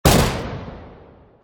DB_Shot.wav